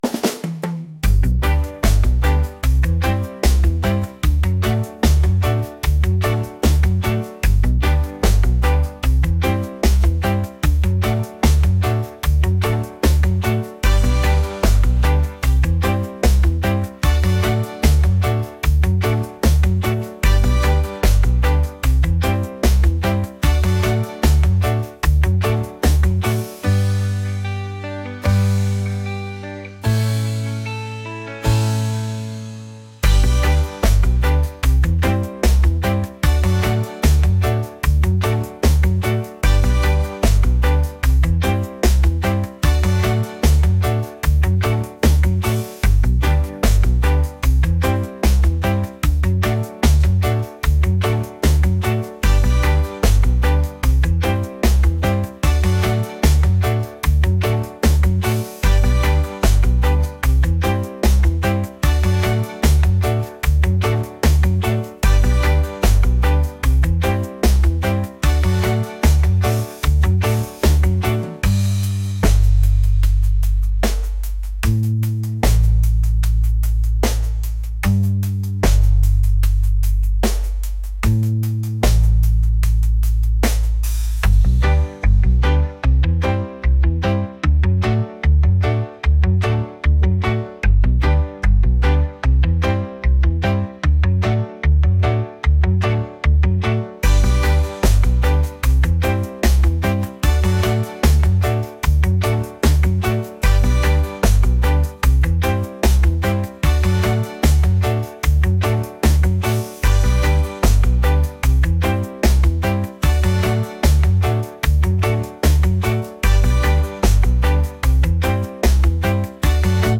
reggae | funk | pop